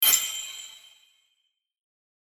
main_reward_btn.mp3